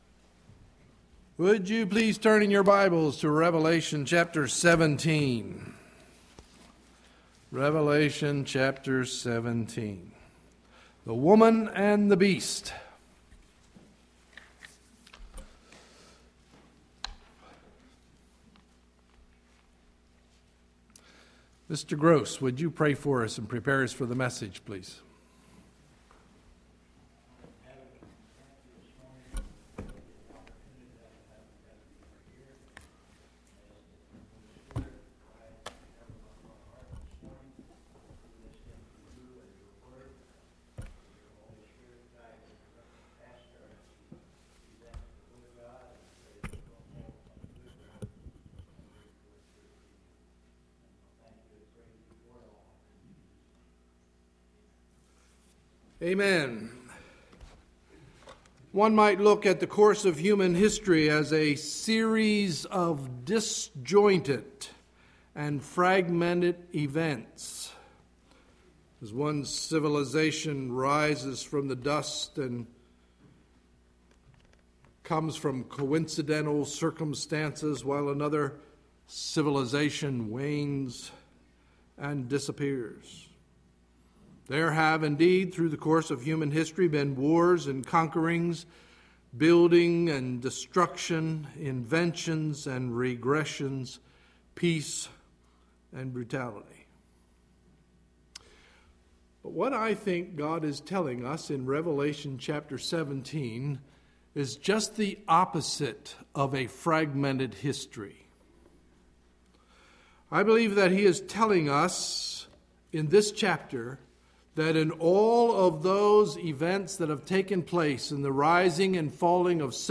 Sunday, October 23, 2011 – Morning Message